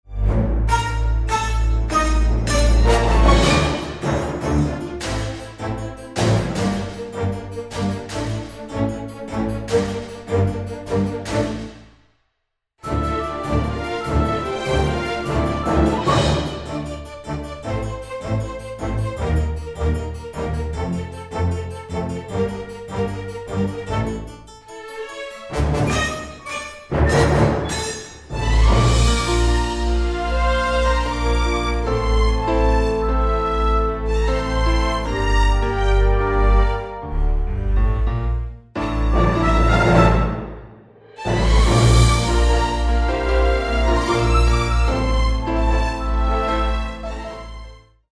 This is a professional backing track of the song
orchestral
Instrumental